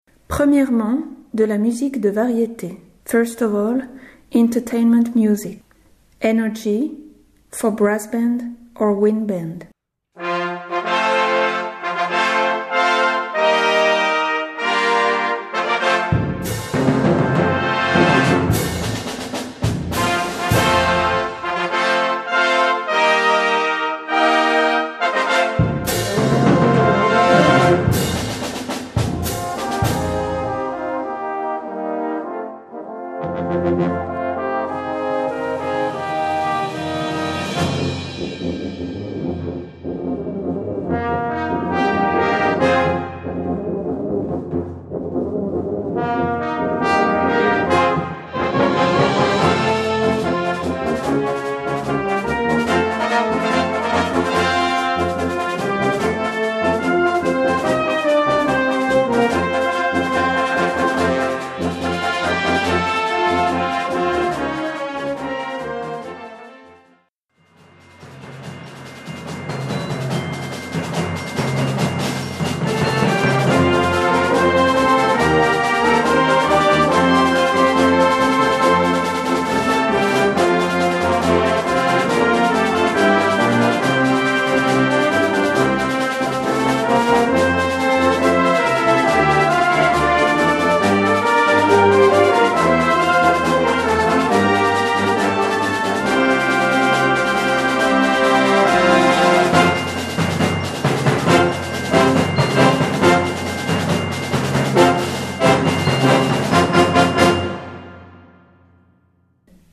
Brass Band
Wind Band (harmonie)
Opening Pieces / Indicatifs